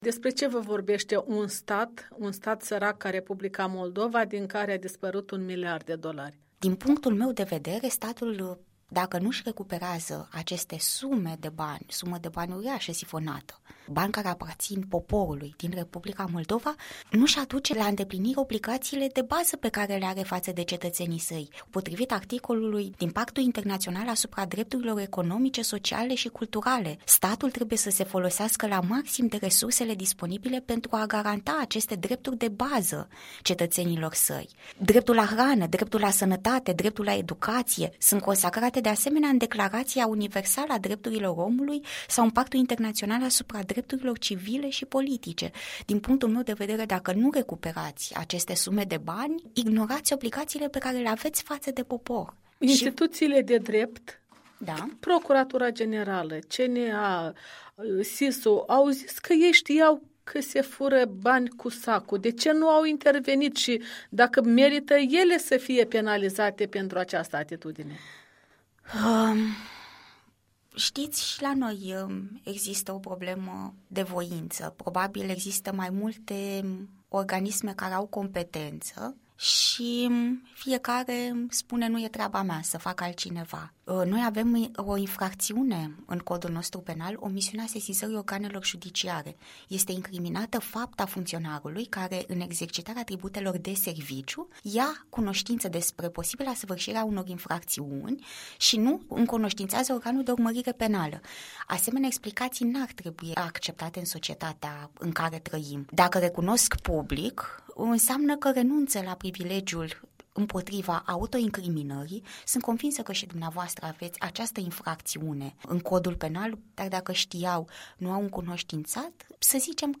Un interviu cu o judecătoare de la Curtea de Apel București.